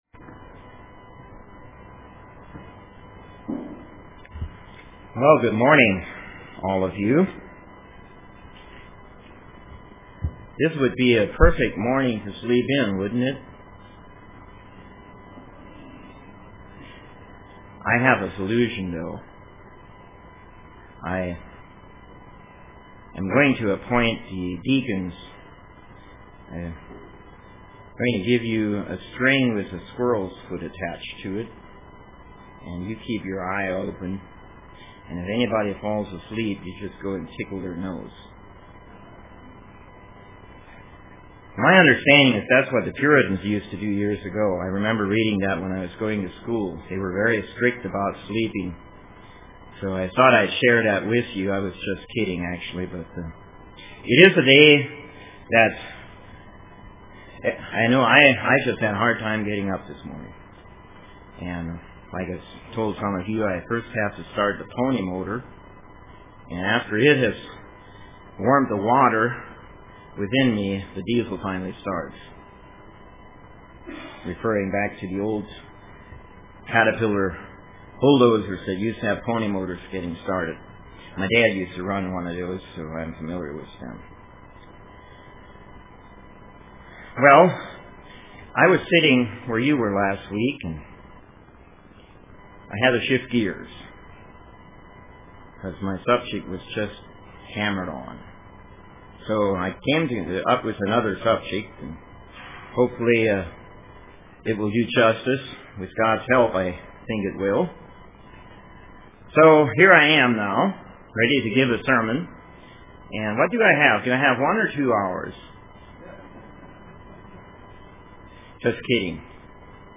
Print Why are we here, what is our motivation.Are we seeking God's direction UCG Sermon Studying the bible?